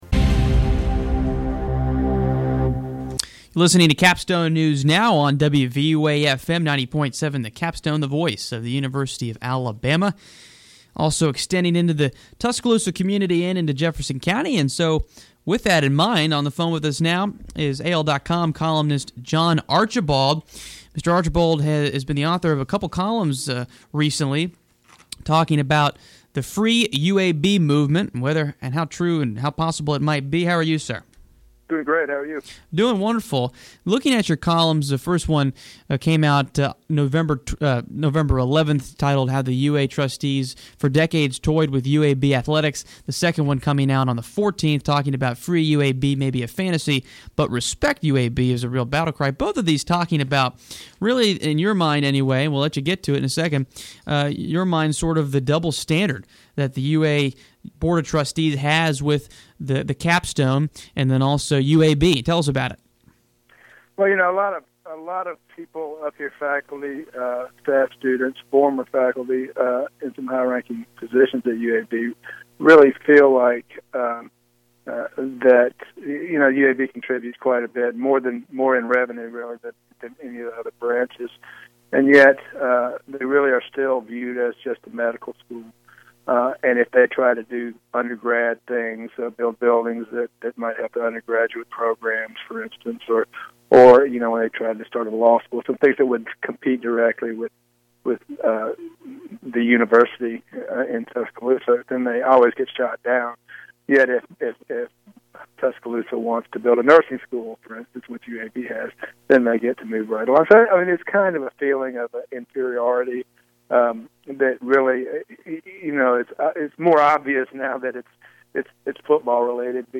AL. It is the only totally student run news broadcast at the University of Alabama.